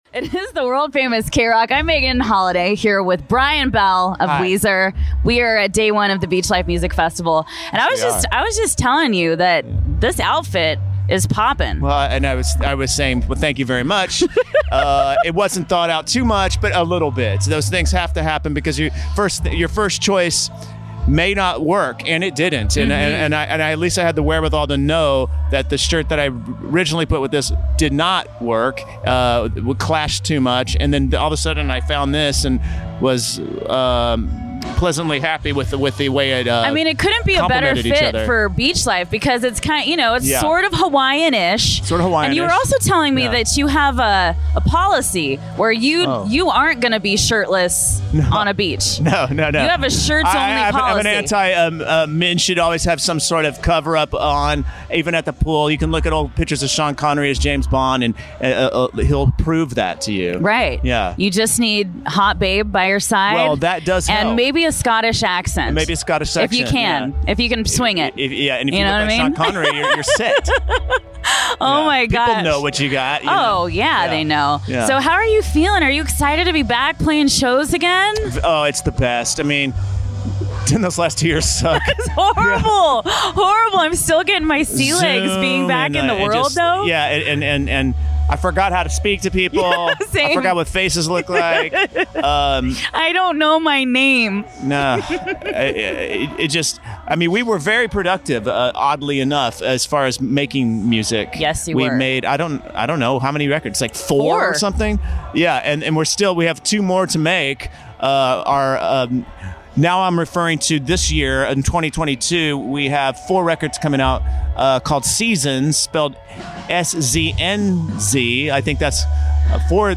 Alternative Artist Interviews Podcast - Weezer | Beachlife Festival | Free Listening on Podbean App